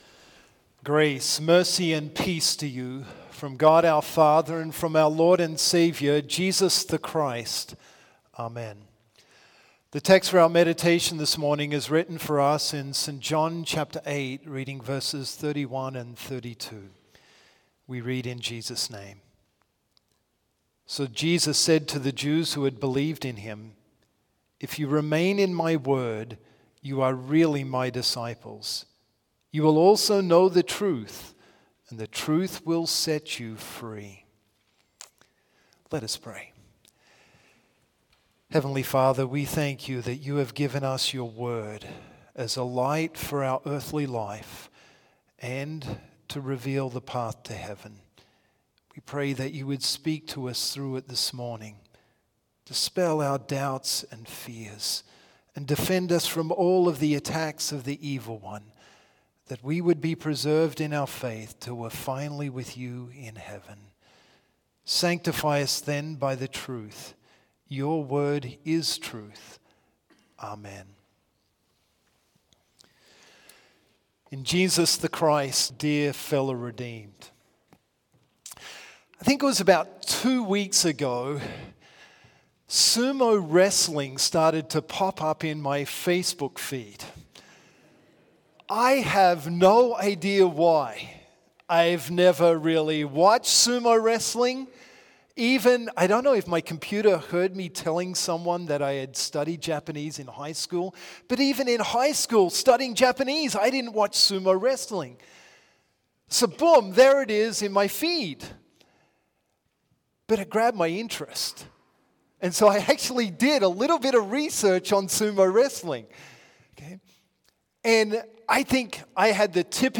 Complete service audio for Chapel - Thursday, October 17, 2024